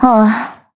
Xerah-Vox-Deny_kr.wav